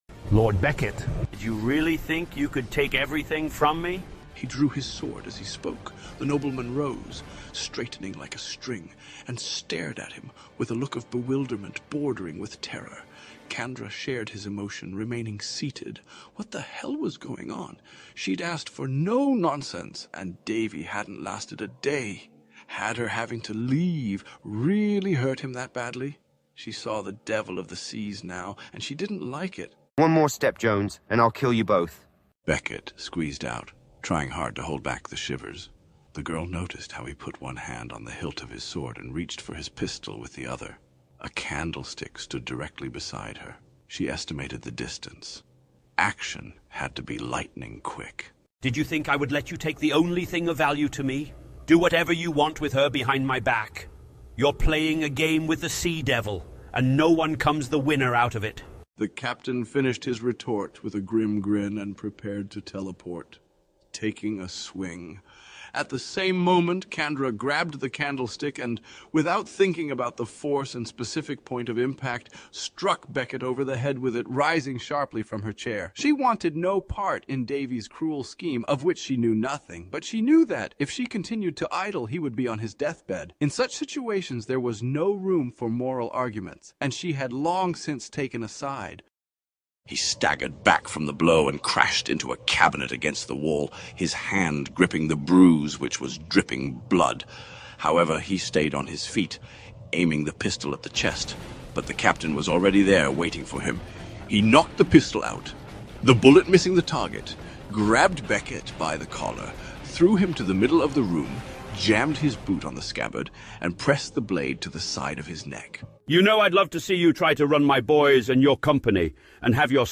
The AI struggles to recreate Davy's accent, however his voice here is still recognizable, and some words and stresses are a very good imitation of his speech.
All the voices were generated by an AI.